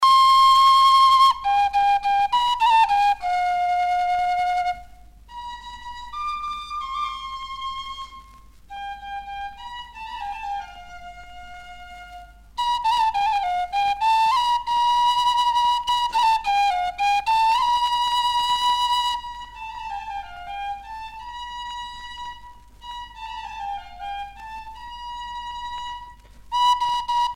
Appels de bergers
Pièce musicale éditée